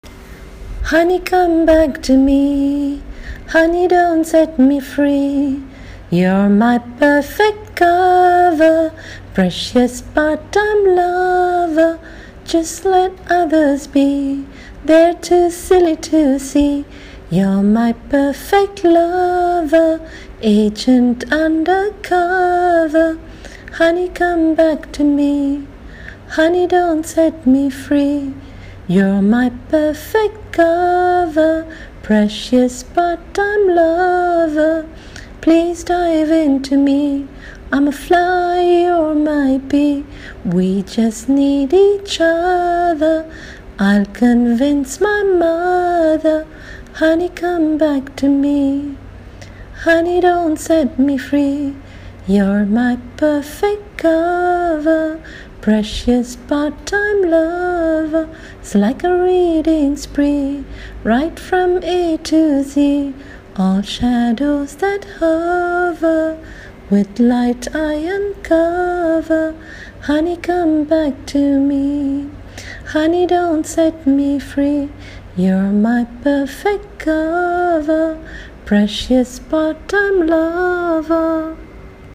Singing using own melody: